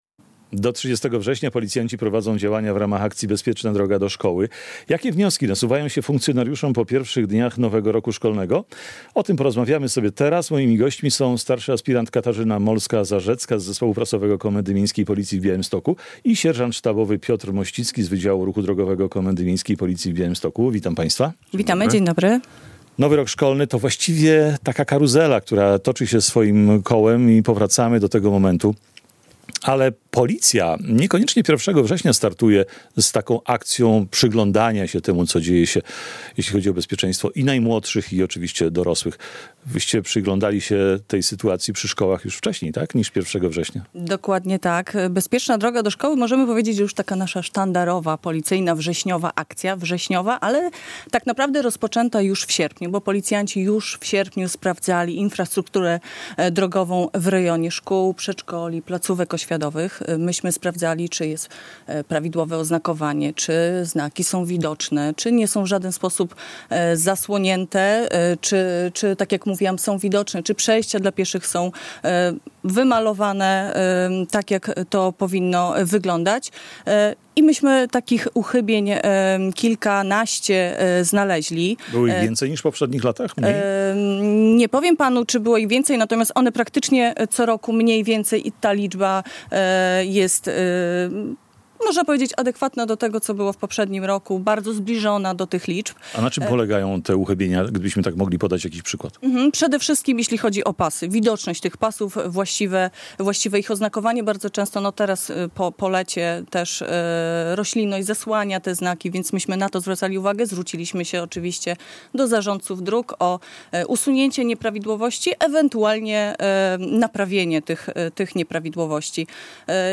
Bezpieczna droga do szkoły - rozmowa